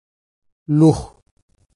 Manlik
/ˈluɡo/